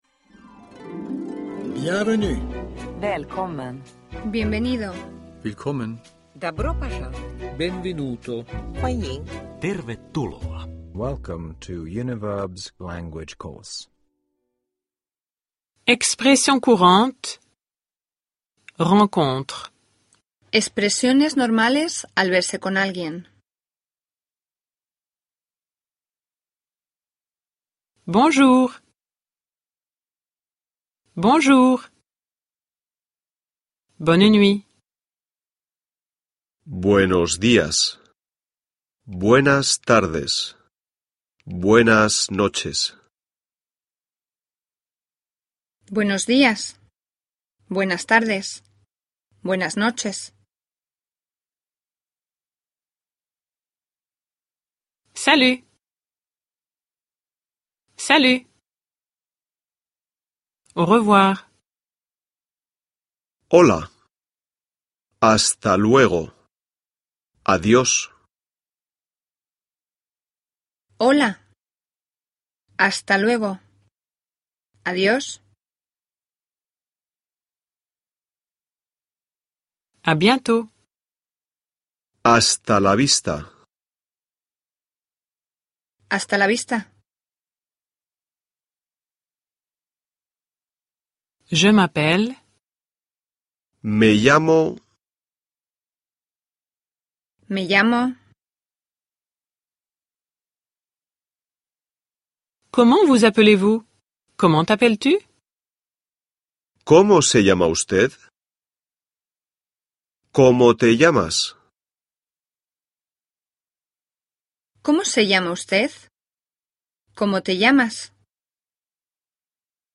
Cours d'espagnol (ljudbok) av Univerb